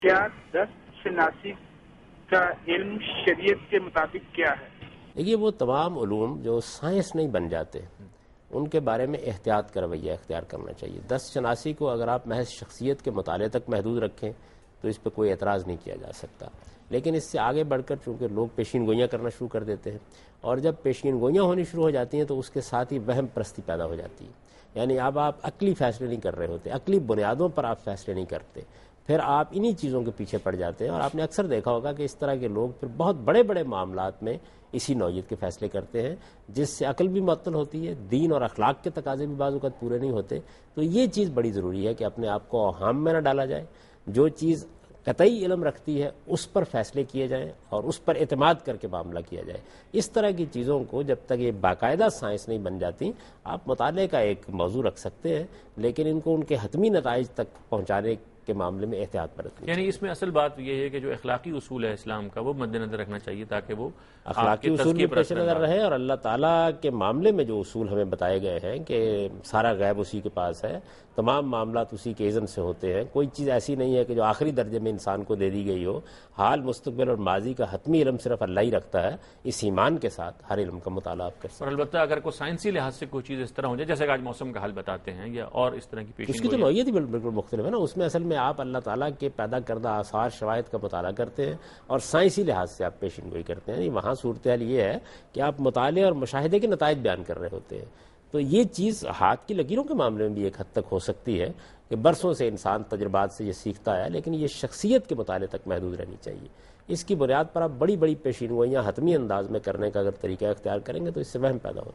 TV Programs
Answer to a Question by Javed Ahmad Ghamidi during a talk show "Deen o Danish" on Duny News TV